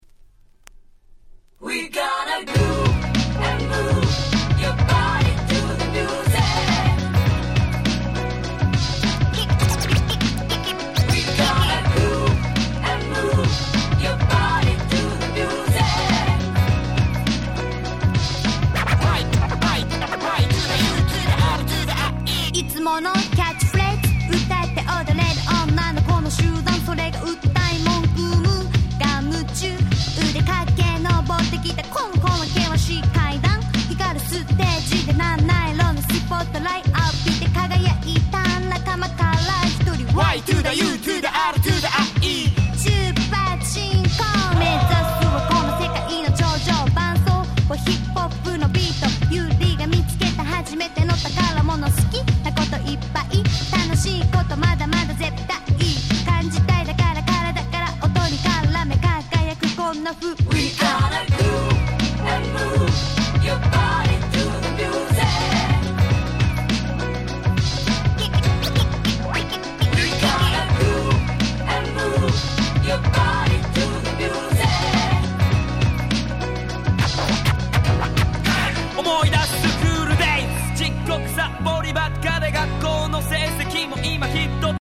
95' J-Rap Classic !!